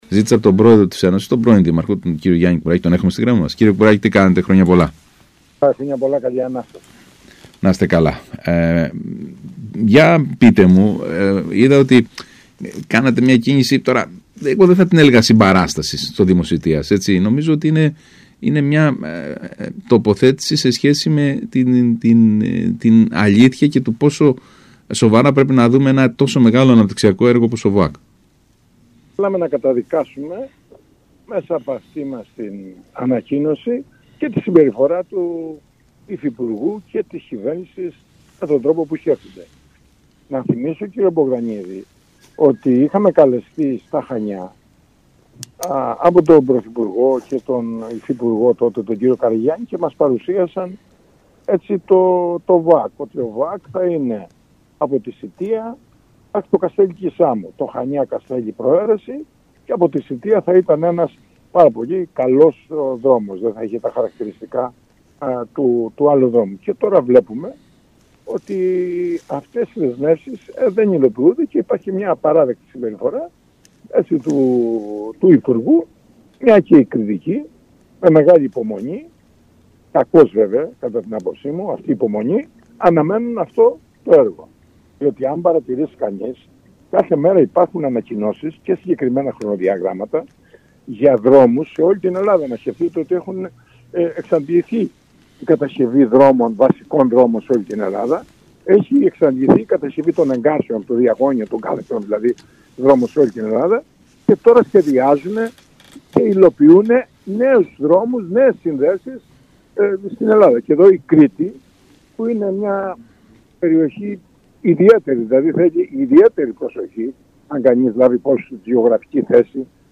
Την αμέριστη συμπαράσταση της Ένωσης Δημάρχων Κρήτης προς τον δήμο Σητείας και τον δήμαρχο κ.Ζερβάκη εξέφρασε μιλώντας στον ΣΚΑΙ Κρήτης 92,1 ο Γιάννης Κουράκης που προανήγγειλε «σοβαρή αντίδραση αμέσως μετά το Πάσχα»!